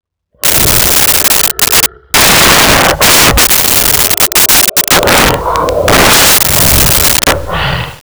Lion
Lion.wav